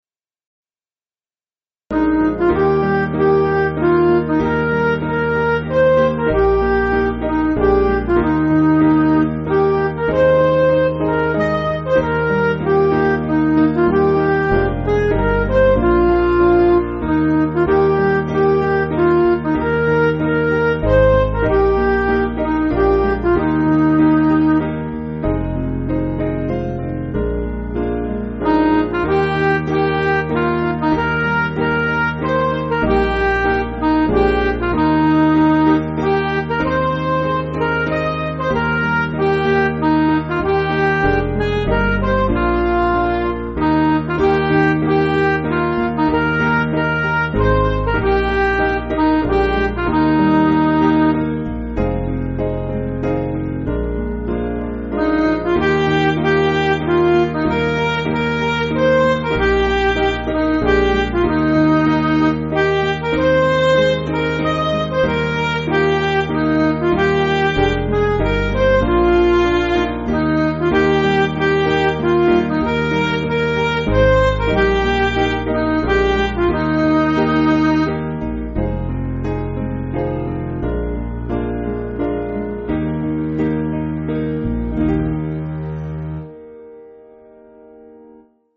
Piano & Instrumental
(CM)   3/Eb